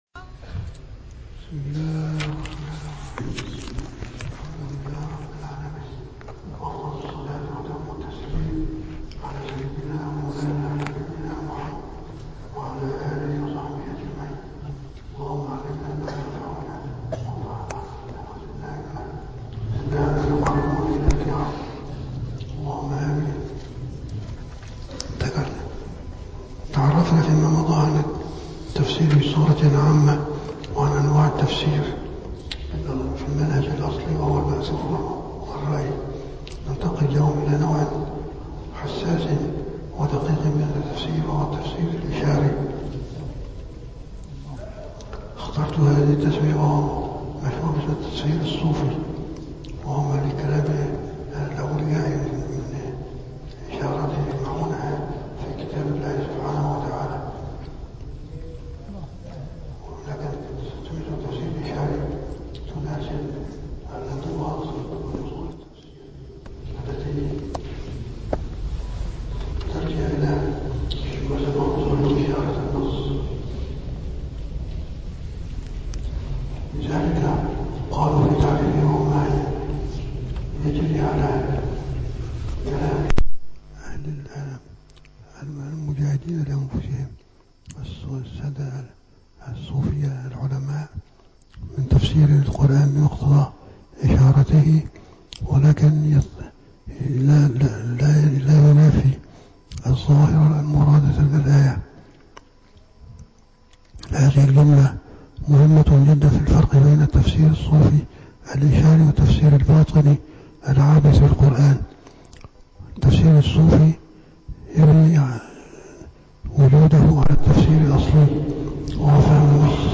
- الدروس العلمية - دورة مختصرة في علوم القرآن الكريم - 6- علوم القرآن الكريم